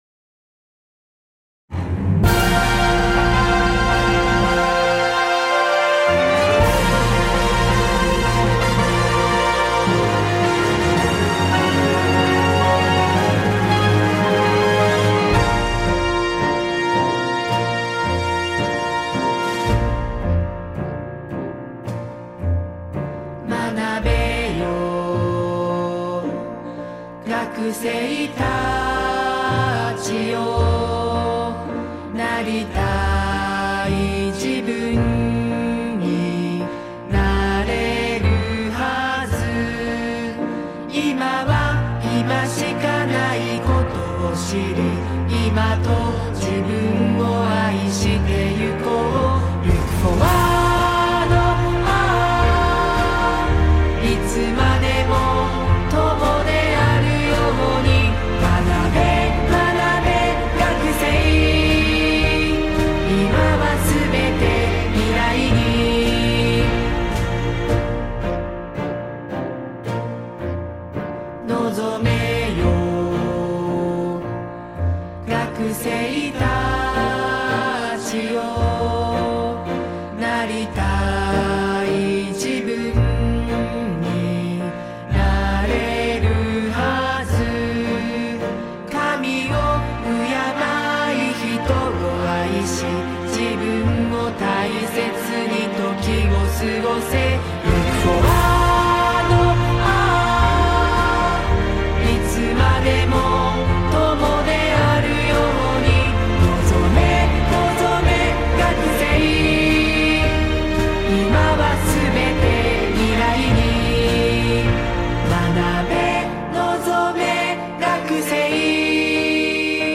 唄：名古屋学院大学アカペラサークル 試聴する ※ダウンロードしたい場合は、下のファイルをクリックしてください。